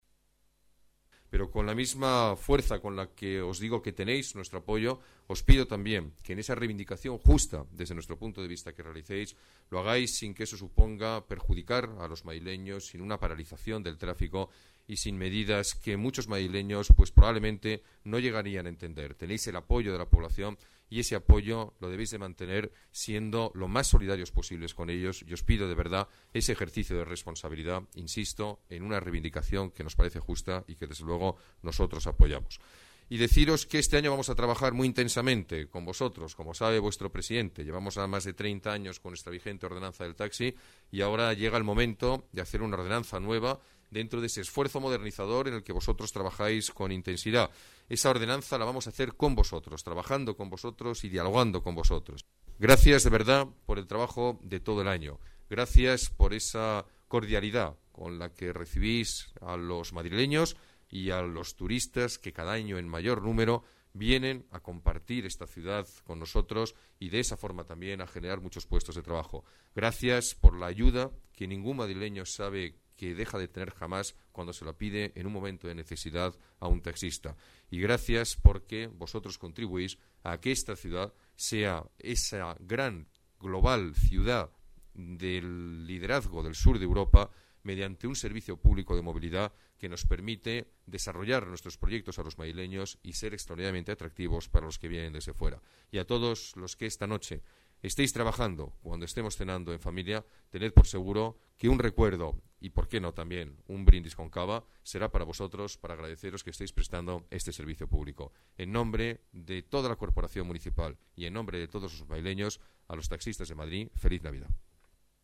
Nueva ventana:Ruiz-Gallardón, alcalde de Madrid: Saludo desde la Federación Profesional del Taxi